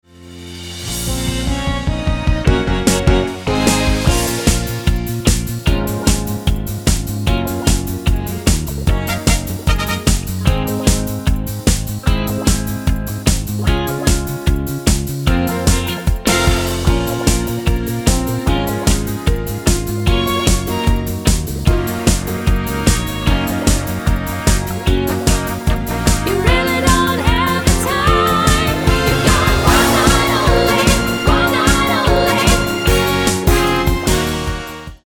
--> MP3 Demo abspielen...
Tonart:Bbm mit Chor